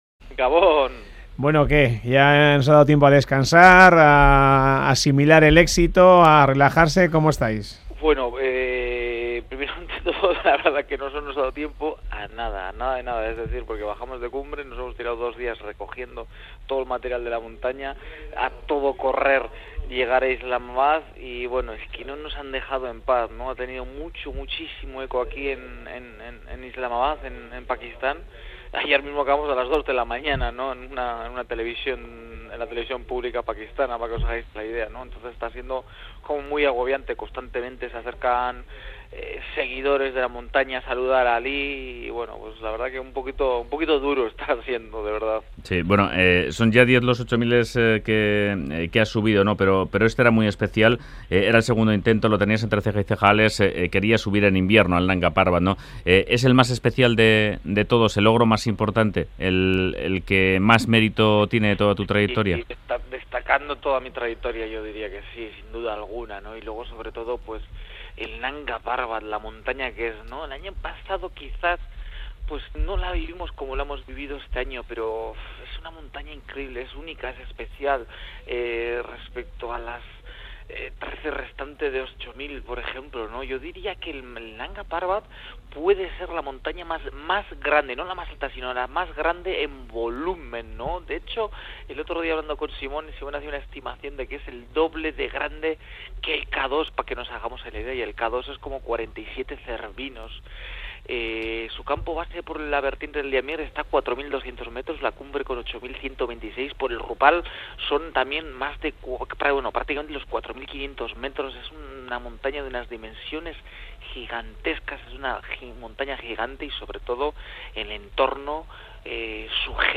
Entrevista a Alex Txikon tras hacer cumbre en el Nanga Parbat | EITB Radio
Entrevista: Cumbre en el Nanga Parbat. El montañero de Lemoa habla para Radio Euskadi tras hacer cumbre en el Nanga Parbat: 'El Nanga es espectacular'.